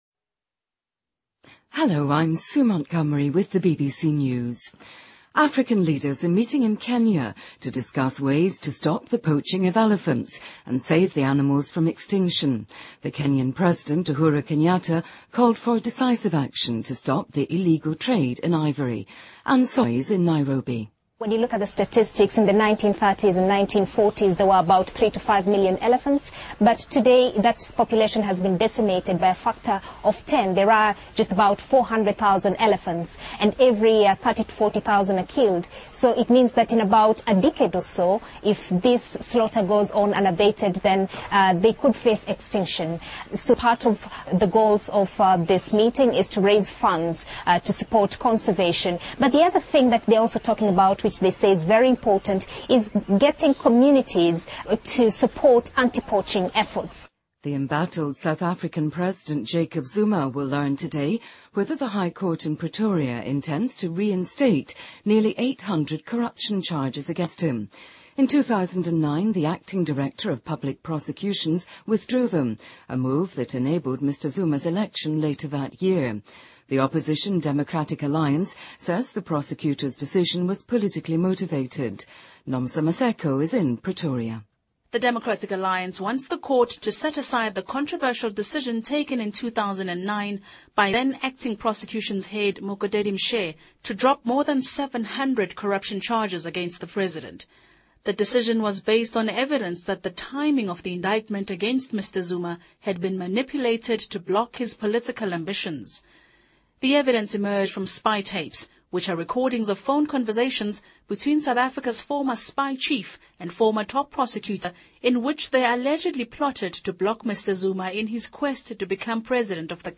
BBC news,珠峰观光直升机可能促发雪崩